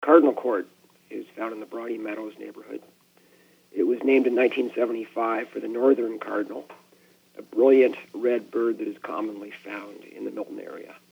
He was gracious enough to still do the interview this week despite suffering a throat injury during a hockey game.